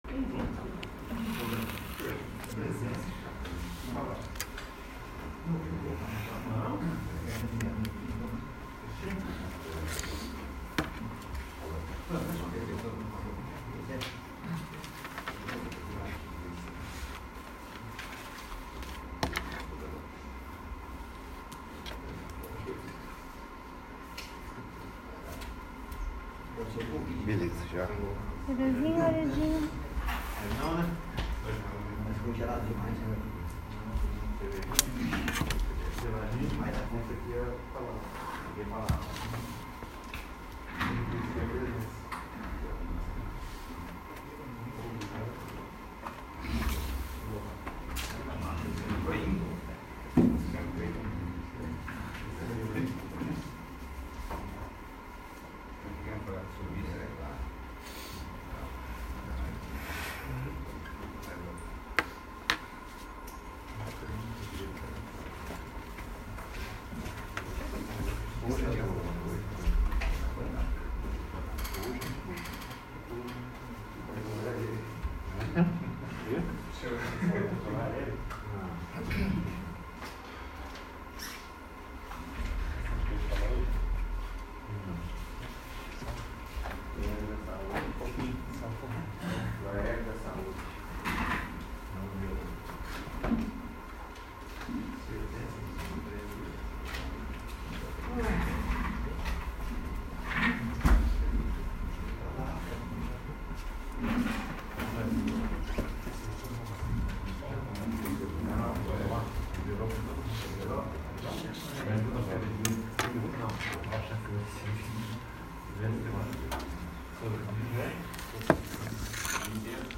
20º. Sessão Ordinária